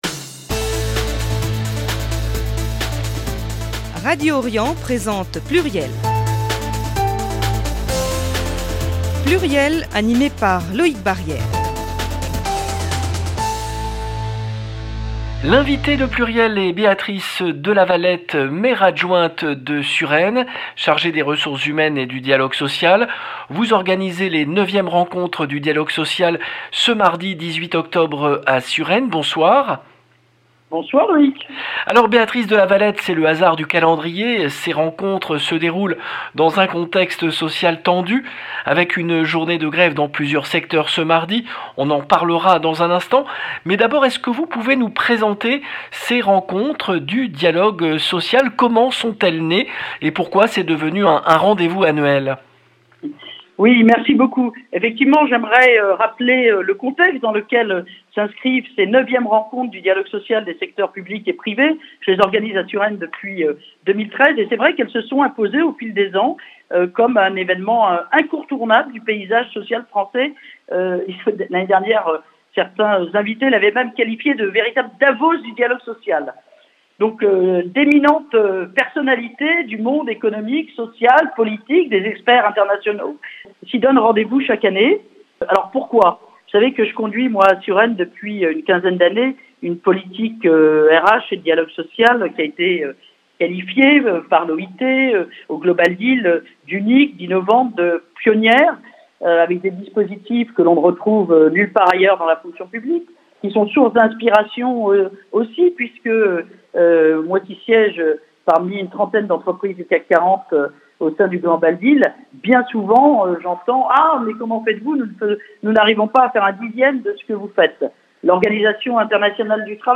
Béatrice de Lavalette, Maire adjointe de Suresnes